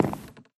step / wood4